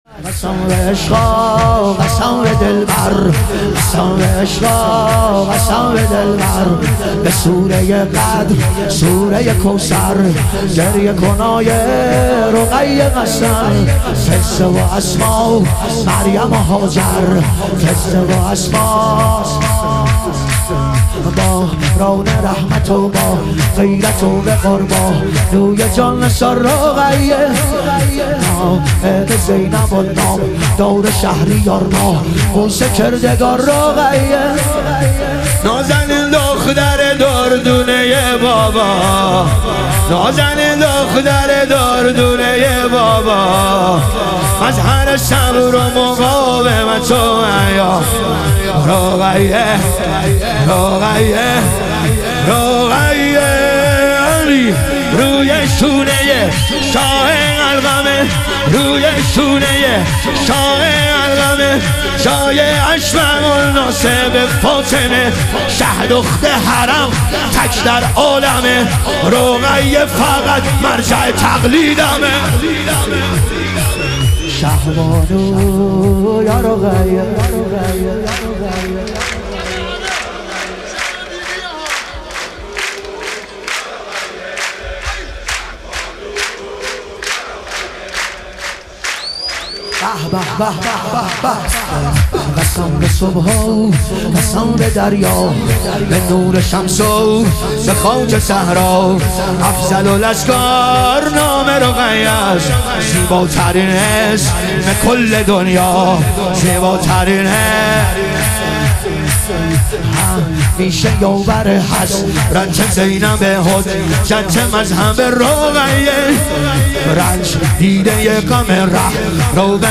ظهور وجود مقدس حضرت رقیه علیها سلام - شور